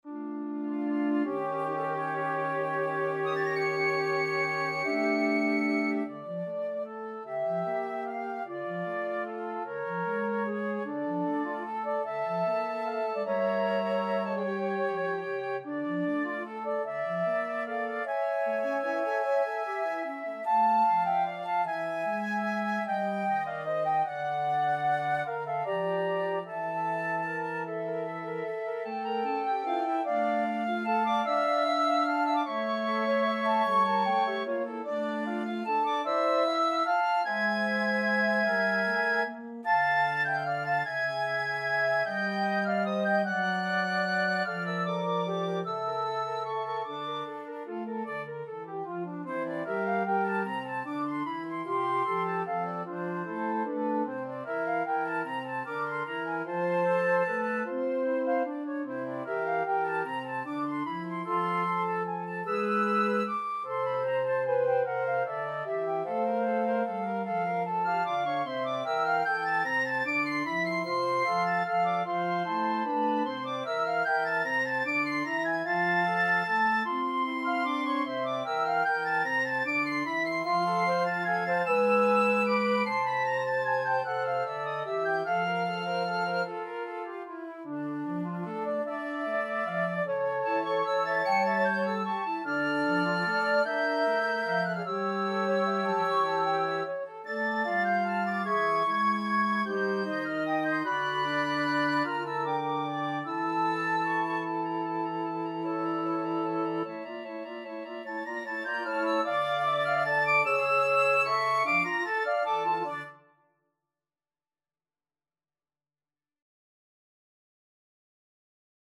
A spooky Halloween piece for Flute Choir
Piccolo / Flute 1 / Flute 2 / Alto Flute in G / Bass Flute